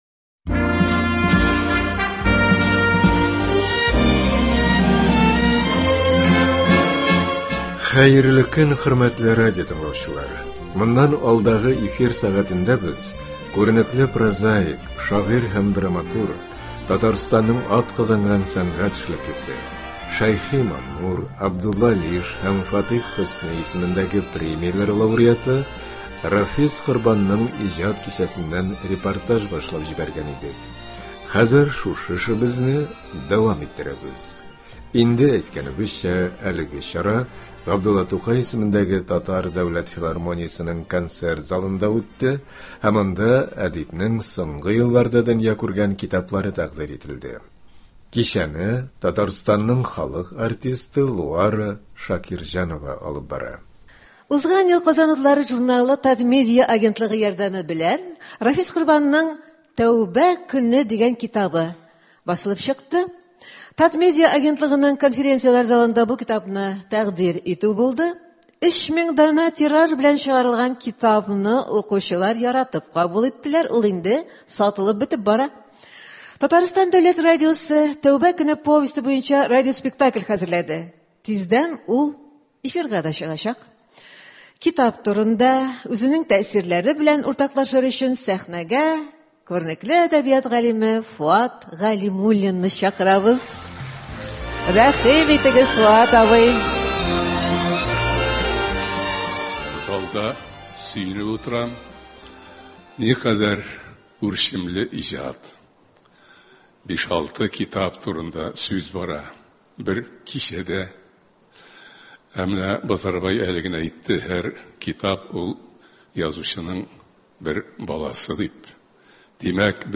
Кичәдә әдипнең соңгы елларда дөнья күргән күп санлы китаплары халыкка тәкъдир ителде. Әлеге чараны без яздырып алдык һәм тамашачылар даирәсен киңәйтеп, аның кайбер өлешләрен, ягъни иң кызыклы урыннарын, эфир киңлекләренә чыгарырга булдык.